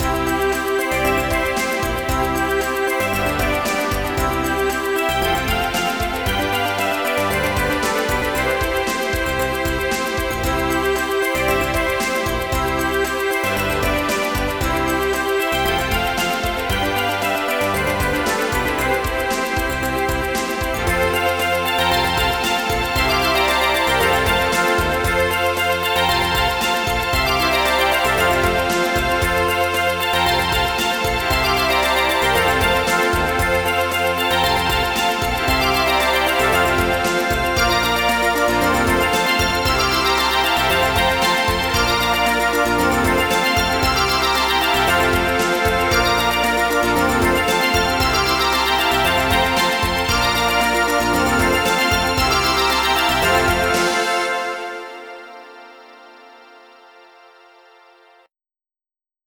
My weakness for cute music is undeniable...!